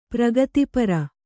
♪ pragati para